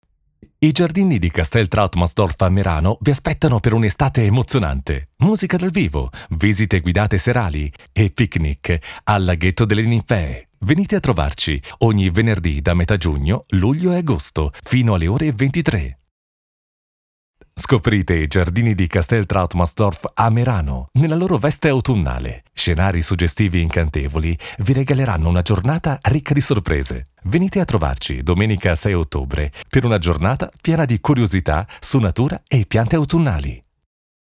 Male
Approachable, Authoritative, Character, Confident, Conversational, Cool, Corporate, Deep, Energetic, Friendly, Funny, Natural, Smooth, Soft, Upbeat, Versatile, Warm, Young
Warm for narrations, dynamic for commercials, smooth and professional for presentations
Microphone: Neumann Tlm 103